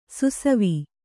♪ susavi